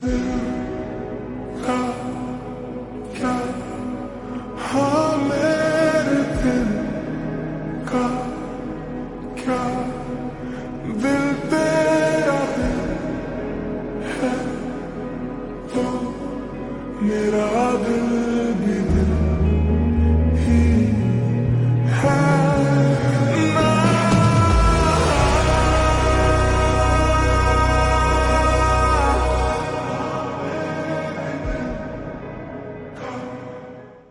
Slow Reverb Version
• Simple and Lofi sound
• High-quality audio
• Crisp and clear sound